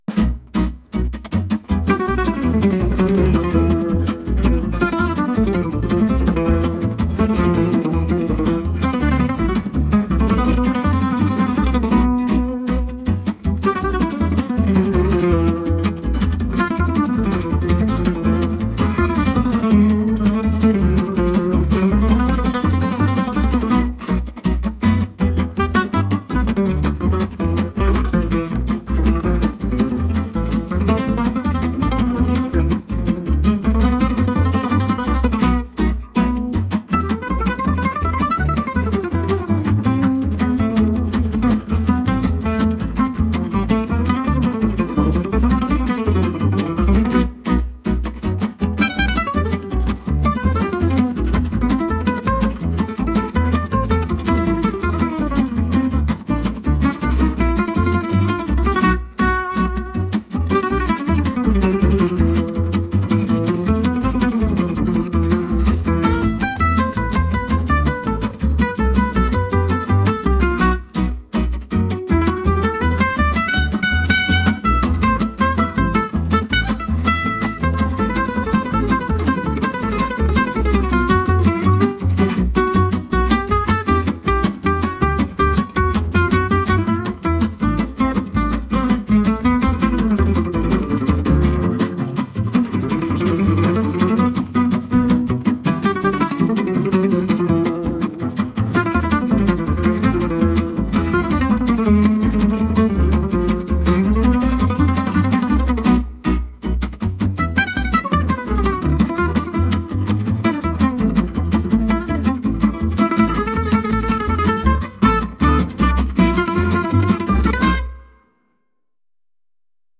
jazz | energetic